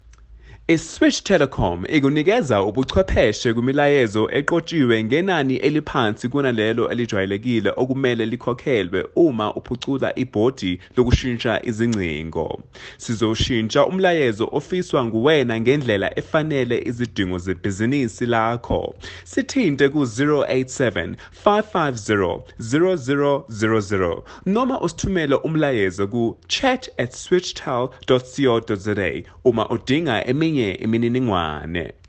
Professional Voice recording (IVR) for your Hosted Switchboard
• Connect with your customers because you are using a professional South African voice artist.